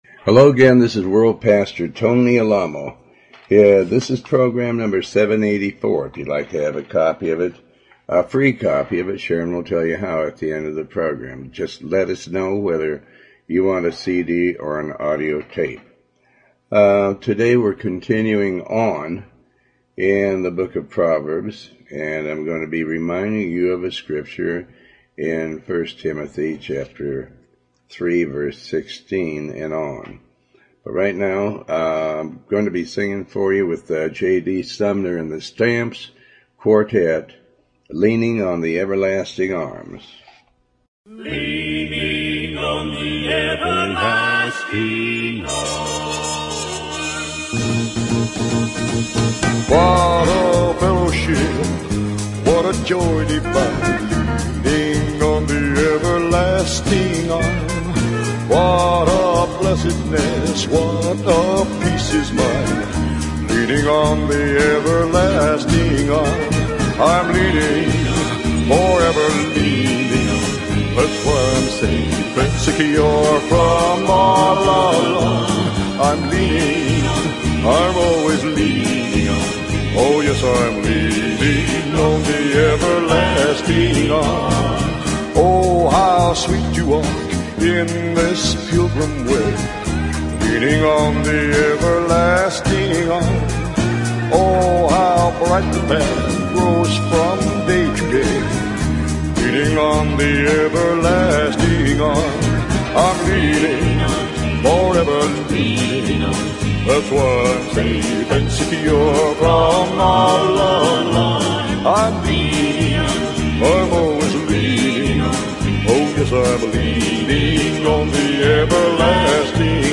Talk Show
Show Host Pastor Tony Alamo